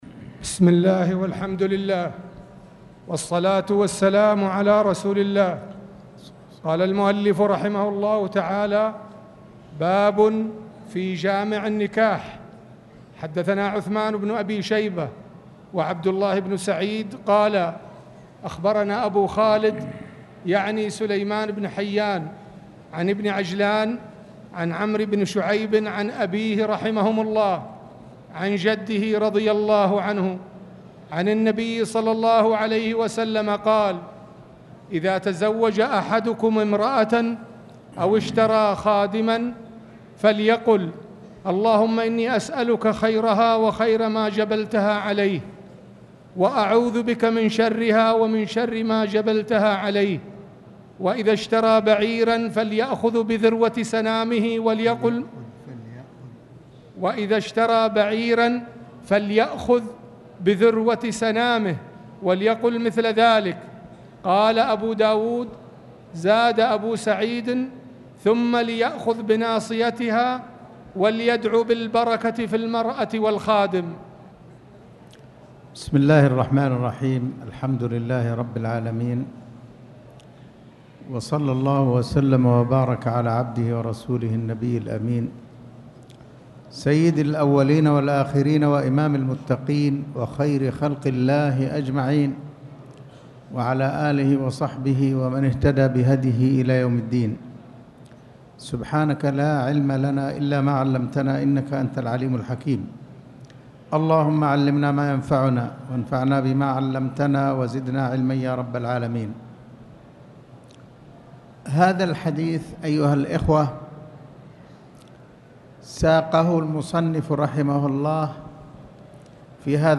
تاريخ النشر ١١ ربيع الأول ١٤٣٨ هـ المكان: المسجد الحرام الشيخ